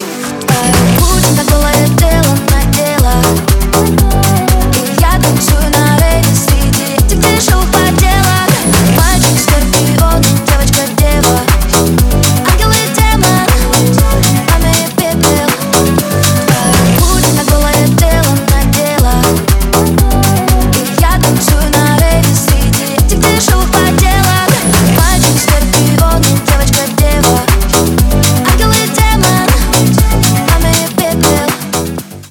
танцевальные
ремиксы